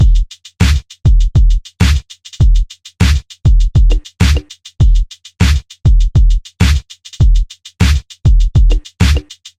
在郊区的鼓环
描述：嘻哈RNB放克
Tag: 100 bpm Hip Hop Loops Drum Loops 1.62 MB wav Key : Unknown Mixcraft